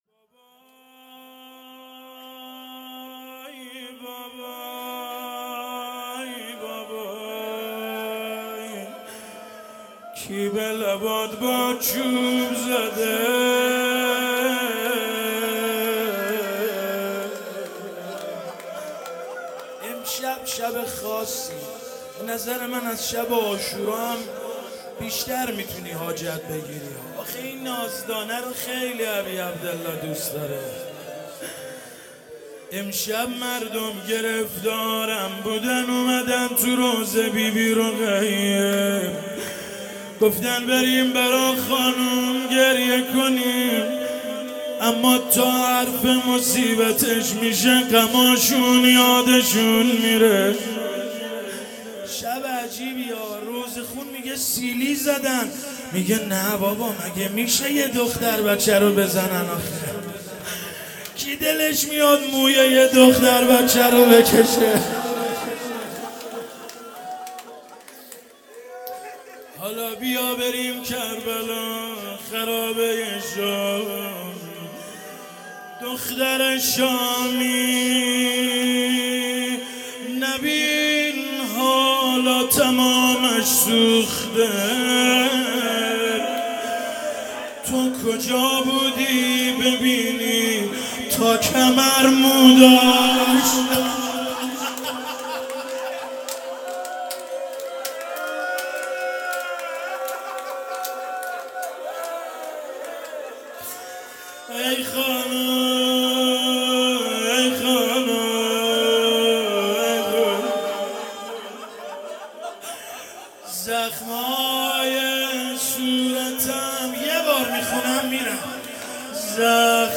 هیئت فرهنگی _ مذهبی مجمع الزهرا(س)
روضه